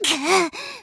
Worms speechbanks
bummer.wav